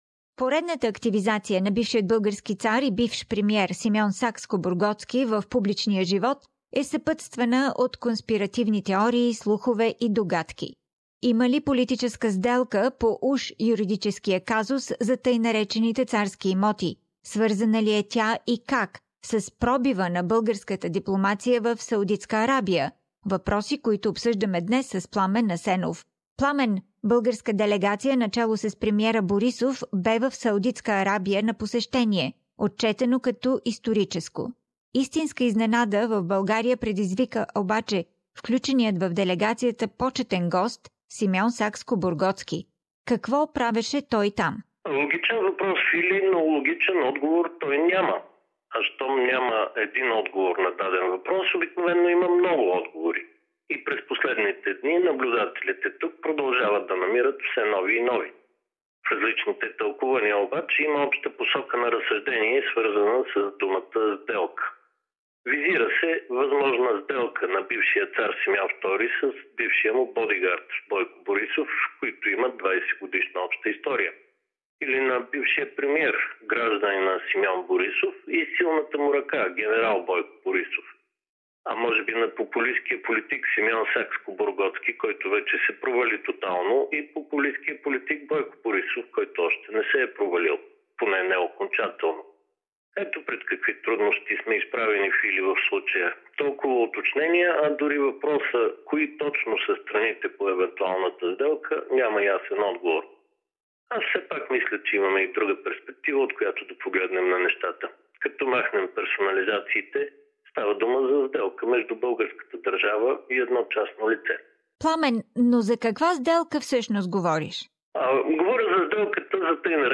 Political analysis